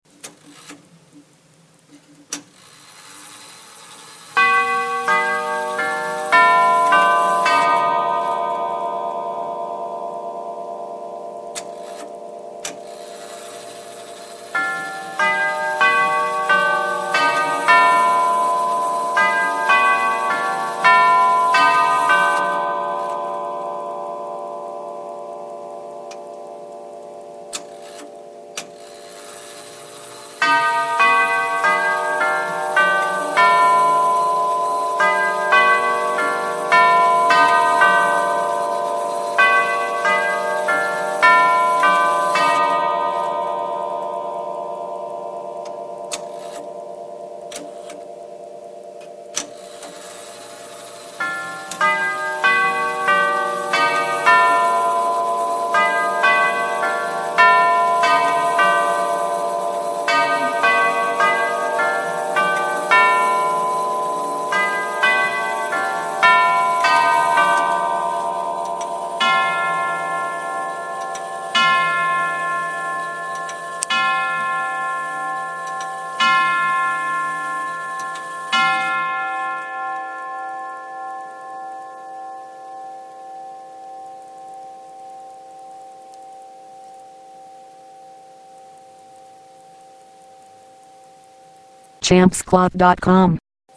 Listen - The Exclusive Herschede Canterbury chime mp3 2198 kb
herschede-250-canterbury.mp3